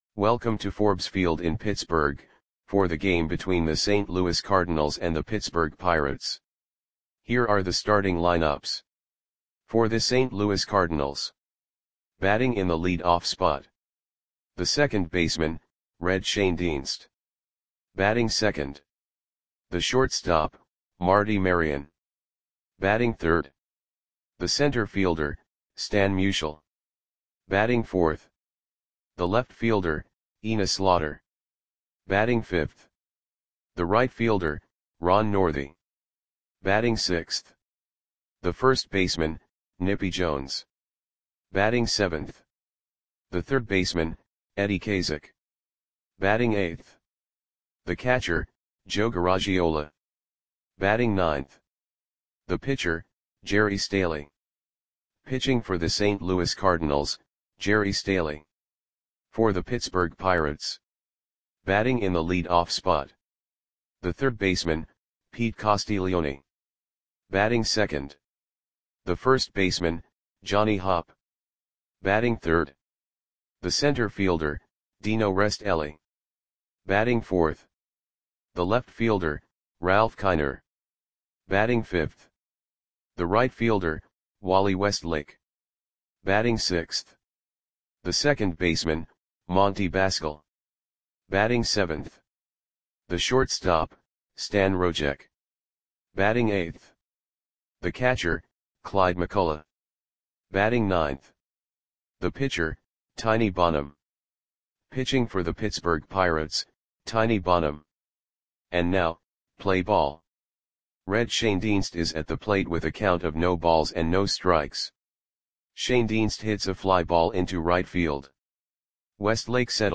Audio Play-by-Play for Pittsburgh Pirates on July 7, 1949
Click the button below to listen to the audio play-by-play.